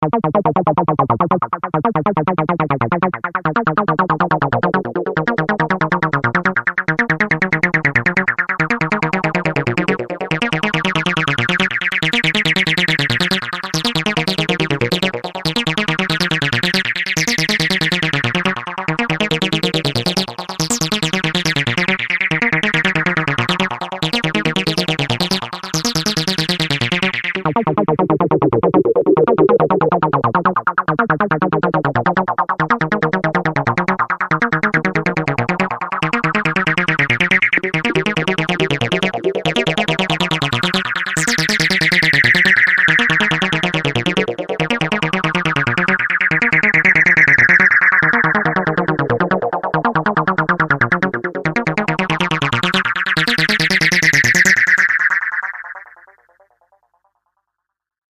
SimSynth v.2.6, dynamics in Wavelab
simsynth_2.6_-_sounds_demo_-_acid_feedbacks.mp3